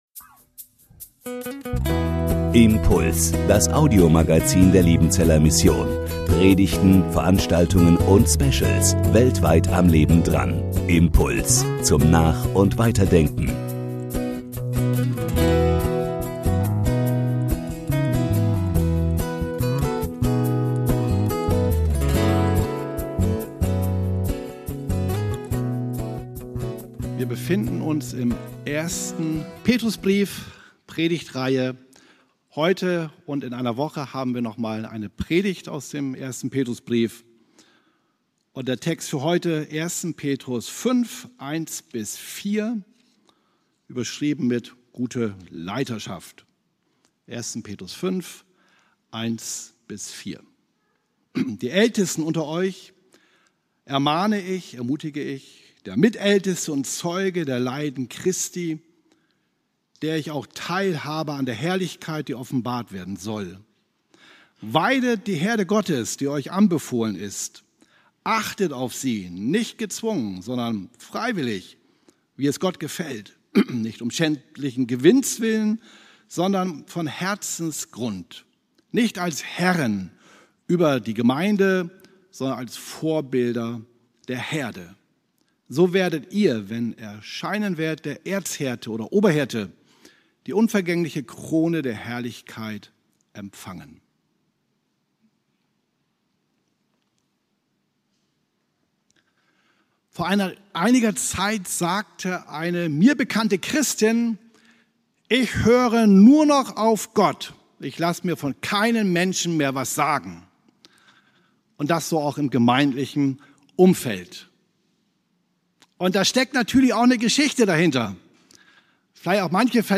Predigt
aus dem MBG-Gottesdienst der Missionsberg-Gemeinde (LM) vom 17.08.2025 mit dem Titel "Gute Leiterschaft"